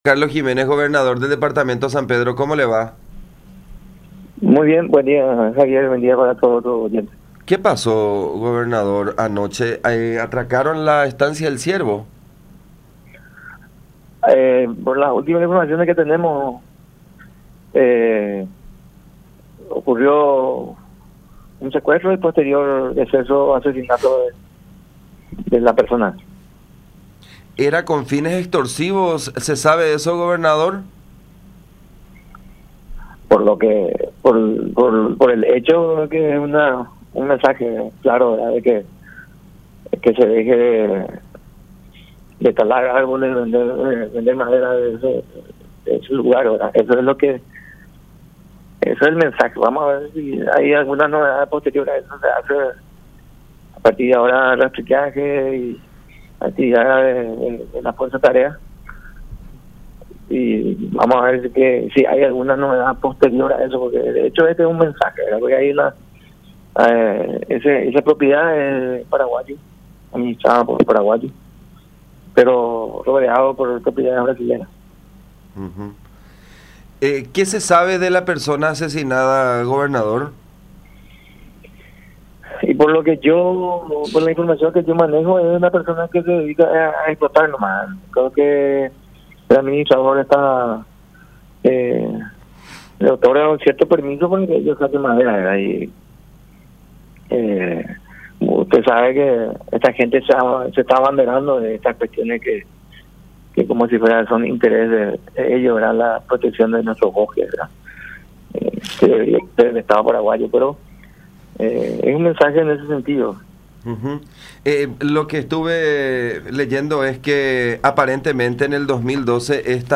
Por su parte, el gobernador del Departamento de San Pedro, Carlos Giménez, dijo que, de acuerdo a los informes que maneja, se produjo un secuestro y un posterior asesinato.
“Por lo que se sabe, (los secuestradores) le dijeron que se deje de talar árboles”, dijo también en conversación con La Unión.
02-CARLOS-GIMÉNEZ.mp3